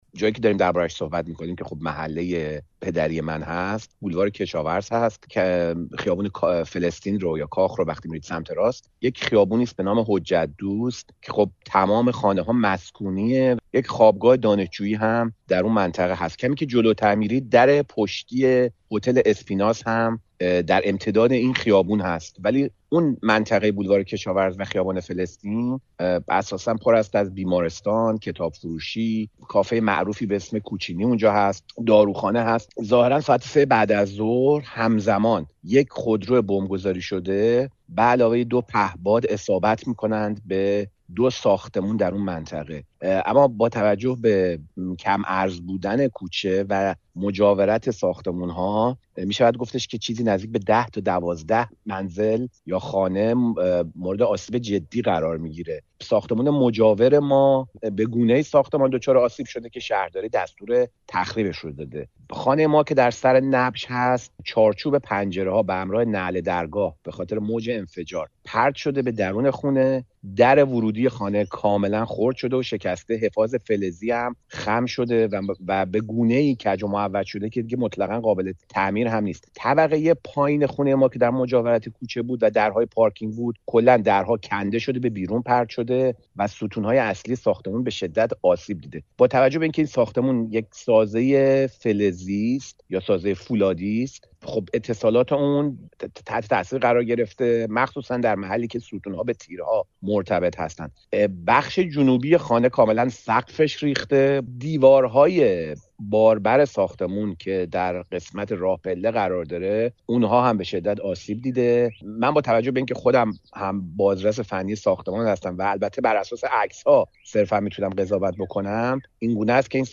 با او دربارهٔ همین موضوع گفت‌وگو کرده‌ایم.